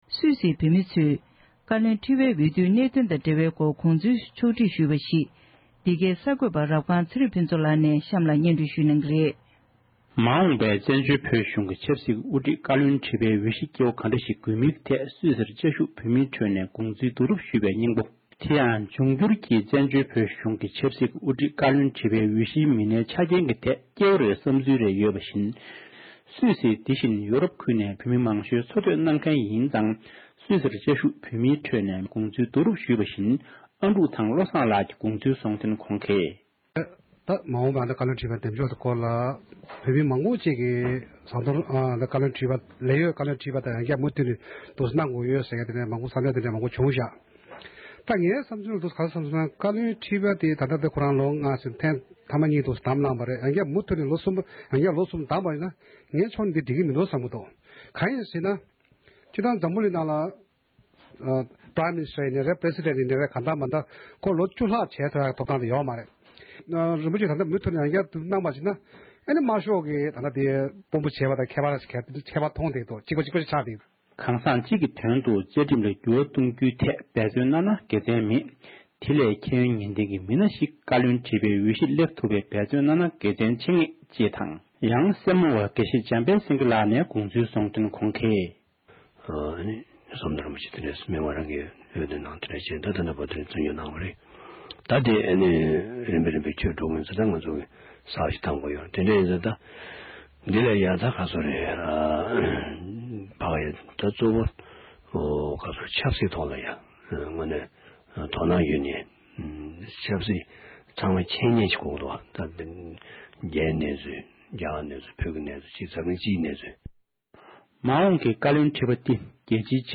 སུད་སི་དང་ལེཀ་ཊེན་སྤྲཡན་བོད་མི་རྣམས་ནས་མ་འོངས་བཀའ་བློན་ཁྲི་པའི་སྐོར་བགྲོ་གླེང་ཞིག་གནང་བཞིན་པ།
སྒྲ་ལྡན་གསར་འགྱུར།